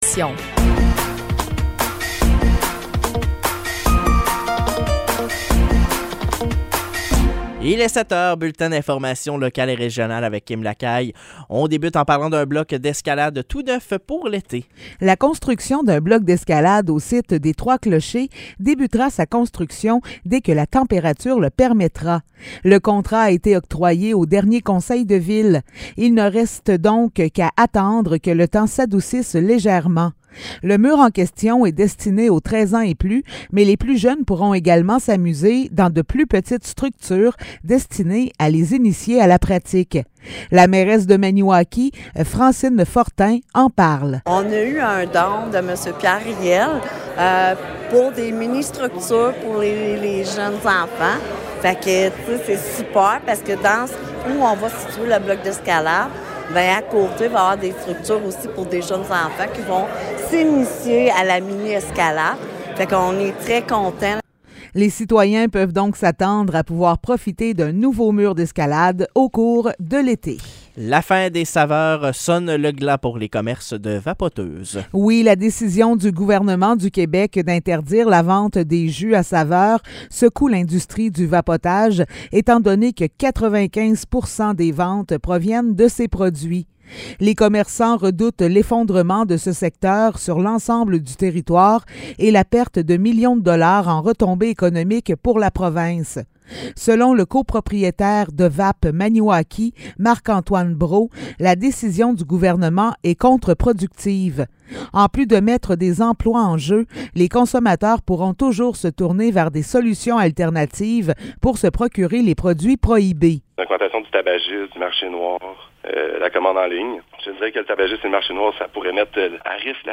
Nouvelles locales - 26 avril 2023 - 7 h